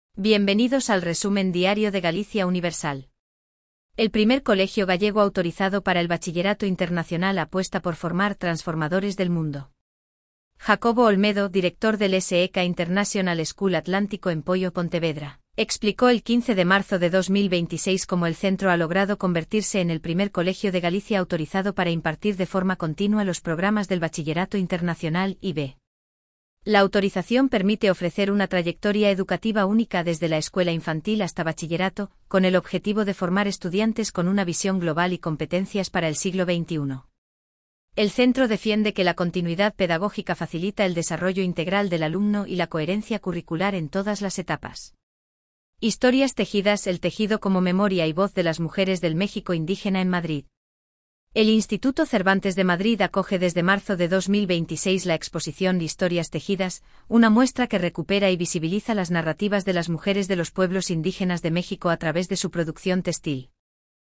Voz: Elvira · Generado automáticamente · 5 noticias